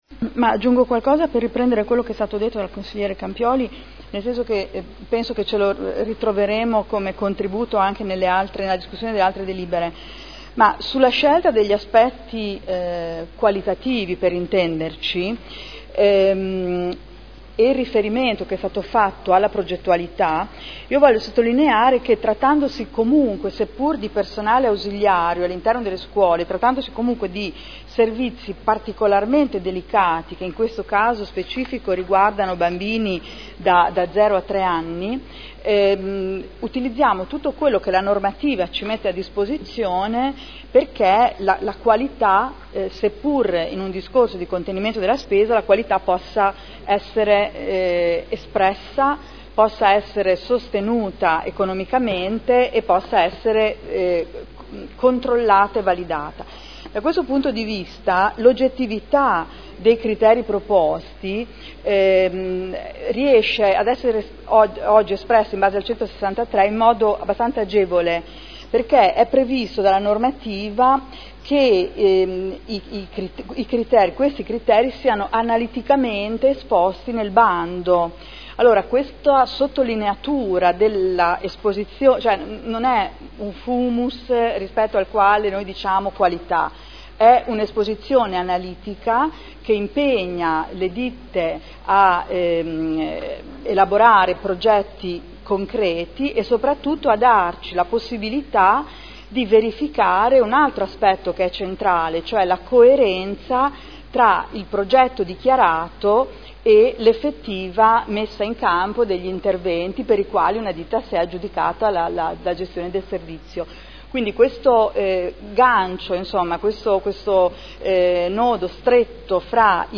Seduta del 07/05/2012. Replica. Linee di indirizzo per l’affidamento dei servizi ausiliari – assistenziali del nido Cipì